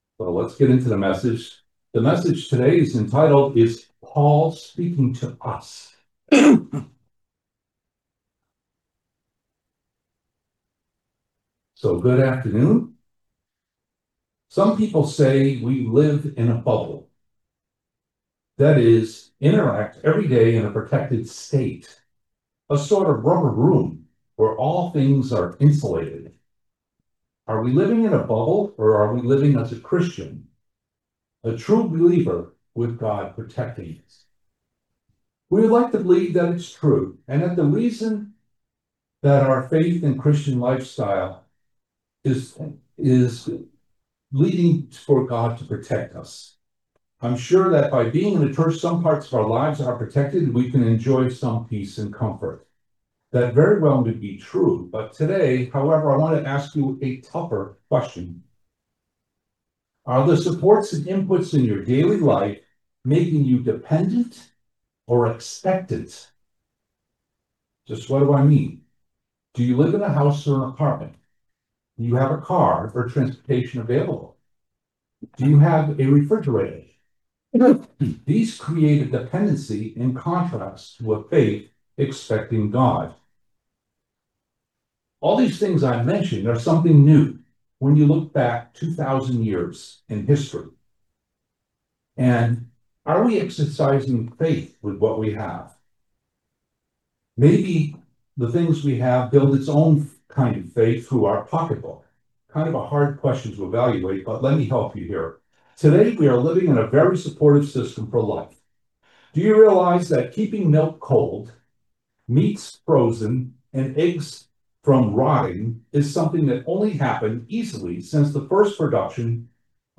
What did the Apostle Paul mean when he said Grace and Peace to you? The answer to this question and more in this video sermon.